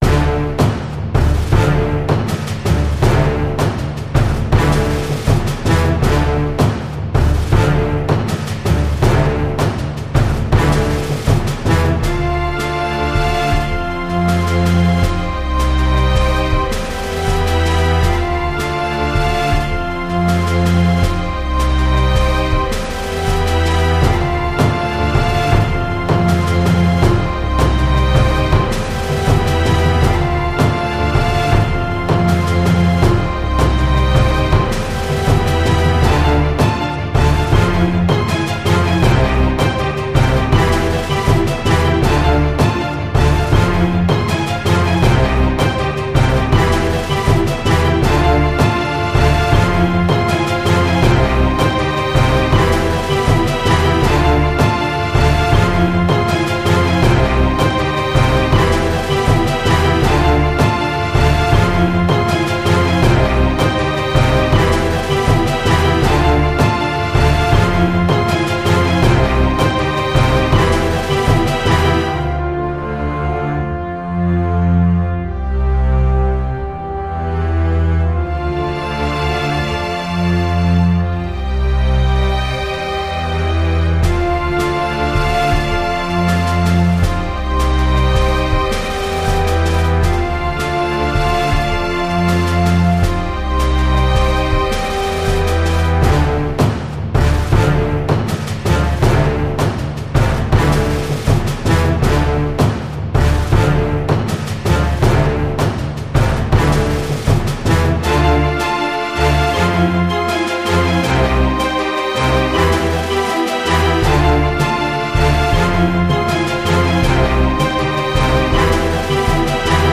دانلود اهنگ بی کلام برای تولید محتوای ی و ساخت کلیپ